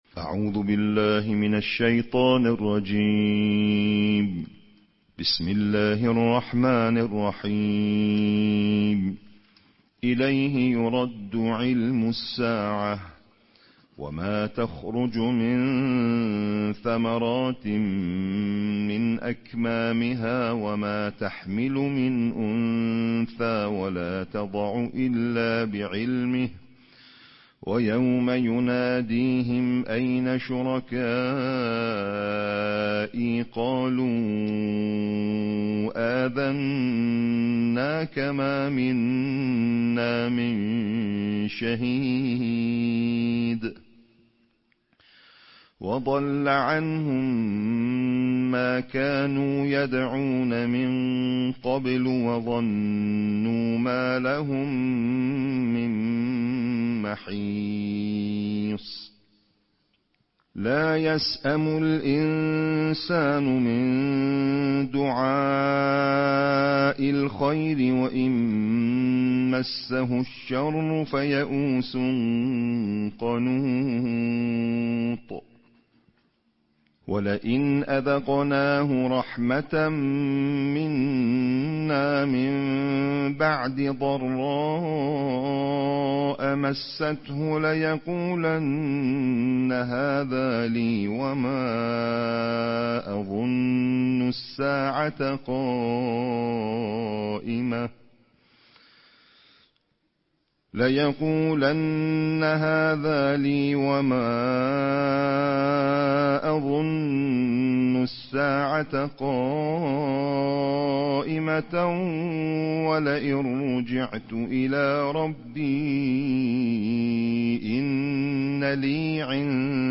نړیوال قارِيان ،د قرآن کریم د پنځویشتمې(۲۵) سپارې یا جزوې د ترتیل قرائت